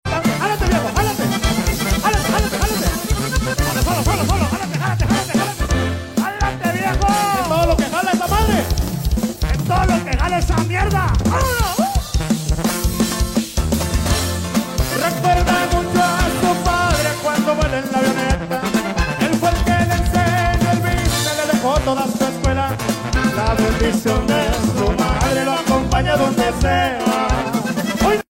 Los marranos haciendo ruido.. 🤣🤣🤣🤣